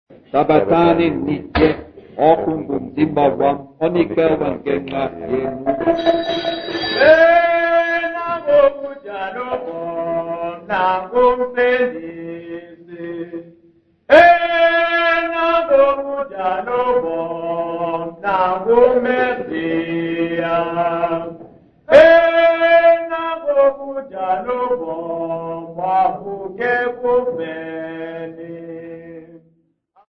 DDC151b-02.mp3 of Catholic Prayer and Response